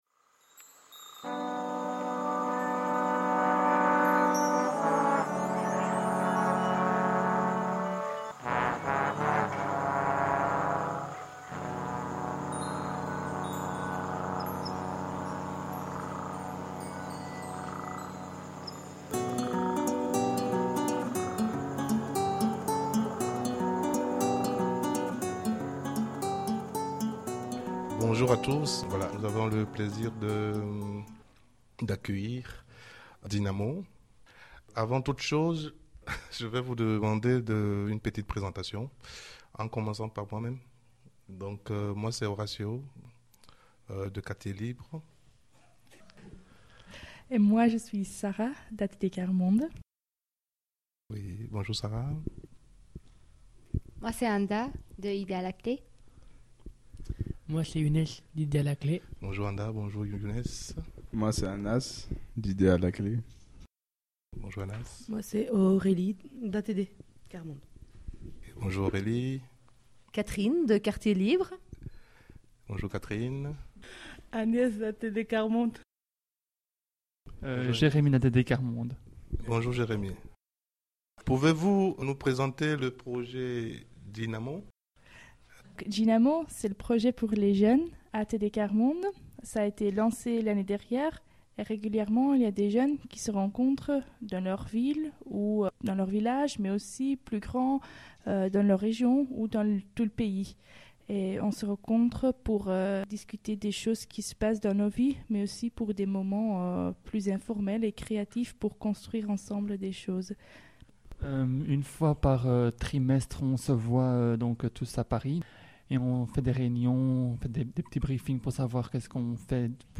Et si la réflexion vous intéresse, écoutez l’émission débat sur la question chômage-emploi réalisé conjointement entre les jeunes de Djynamo (ATD ¼ Monde) et les jeunes d’Idéeal à Clé en cliquant ici